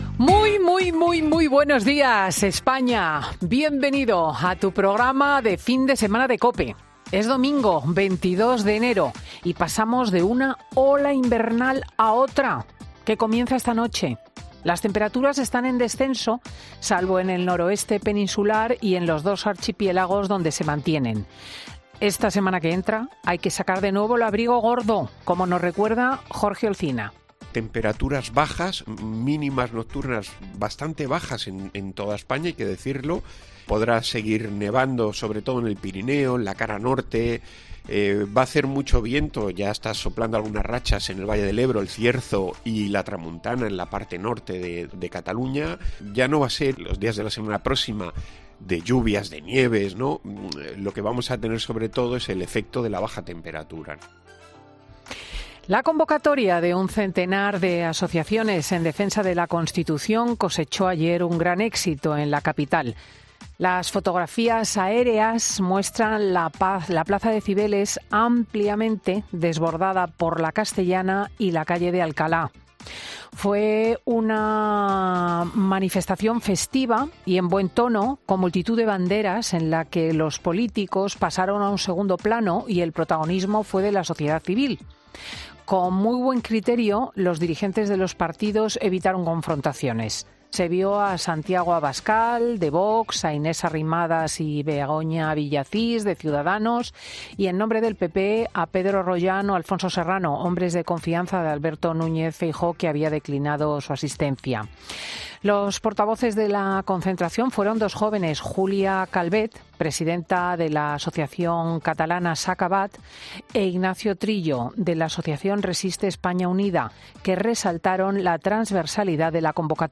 Escucha el monólogo de Cristina López Schlichting de este domingo 22 de enero de 2023